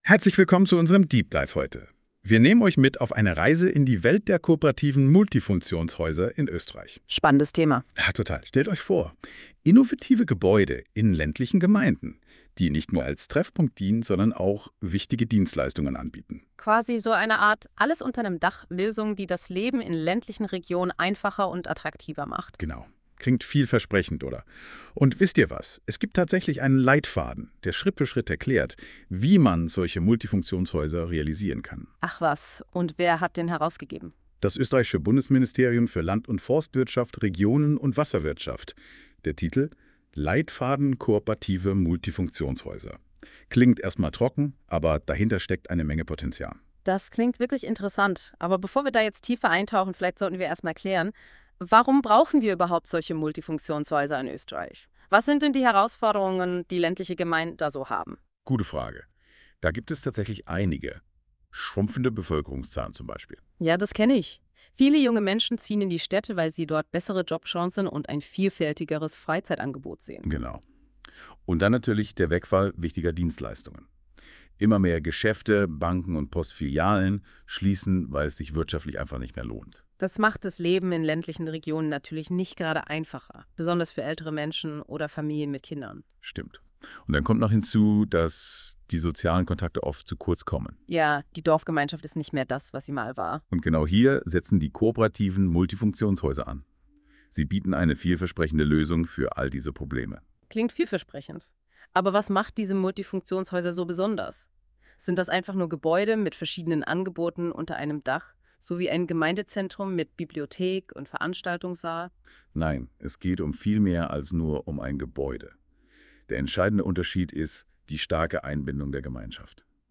Podcast zum Leitfaden der Kooperativen Multifunktionshäuser Zum Leitfaden der Kooperativen Multifunktionshäuser gibt es hier nun eine mit Hilfe von KI erstellte Podcastfolge, in der die Themen des Leitfadens aufgegriffen und behandelt werden. In dieser Folge werden die Inhalte des Leitfadens zu Kooperativen Multifunktionshäusern in Form eines Deep Dives ausführlich besprochen.